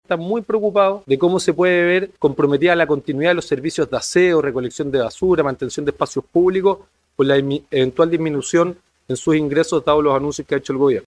Asimismo, el diputado Jorge Brito (FA) apuntó a la falta de claridad sobre el futuro del impuesto territorial, clave para el financiamiento de los municipios.